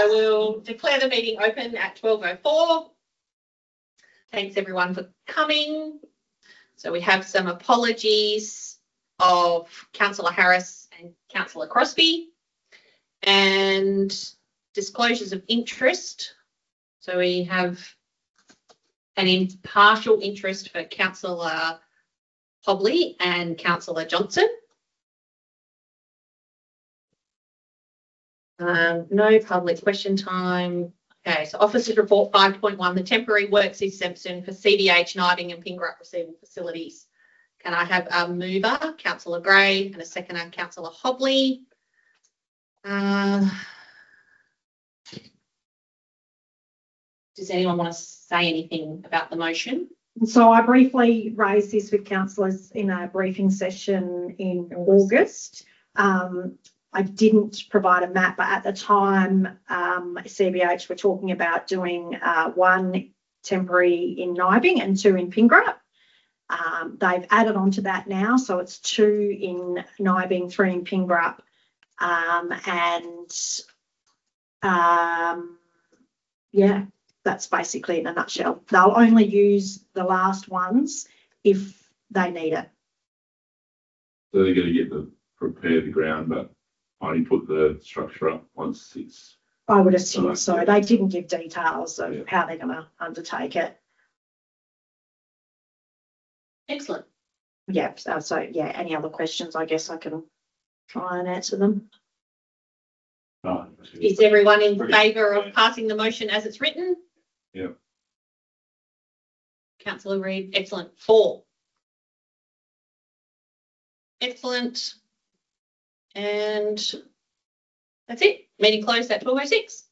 Audio Recording – Special Council Meeting 14 October 2025